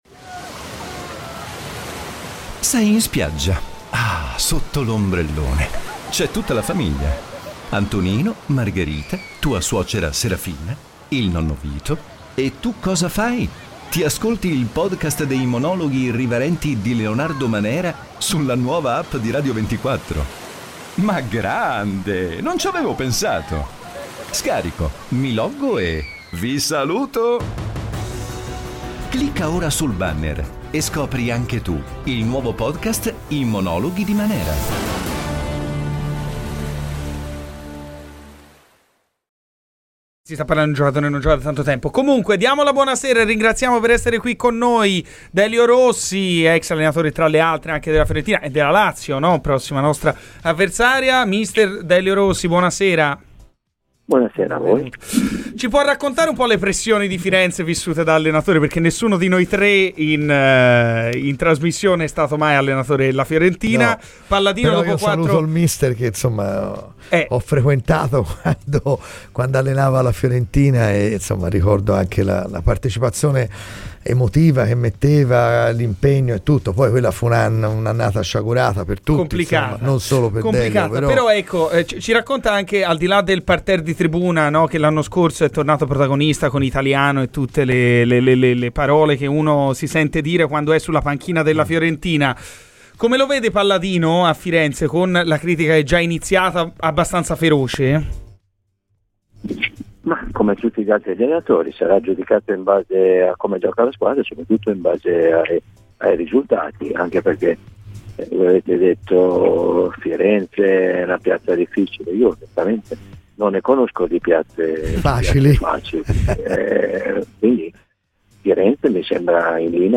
Delio Rossi, ex tecnico viola, ha parlato a Radio FirenzeViola durante Garrisca al Vento.